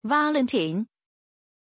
Pronunciation of Vallentin
pronunciation-vallentin-zh.mp3